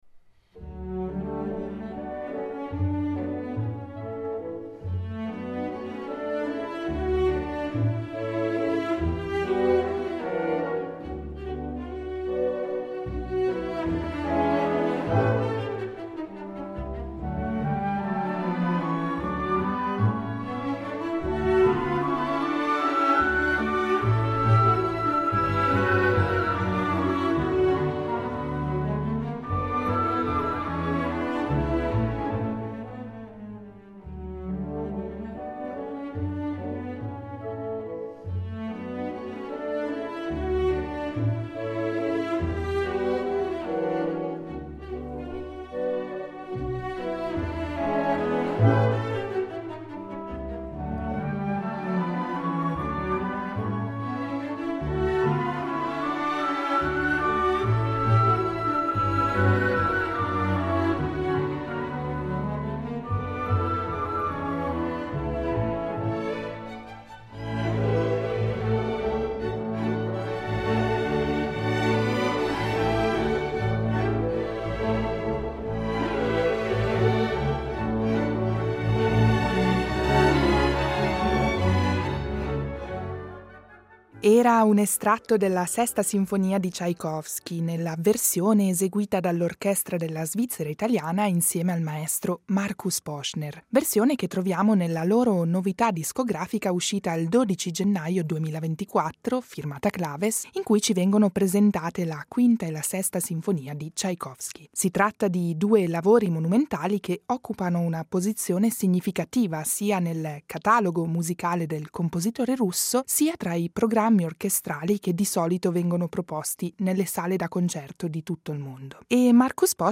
Intervista al direttore d’orchestra sul suo disco Symphonies 5 & 6, Tchaikovsky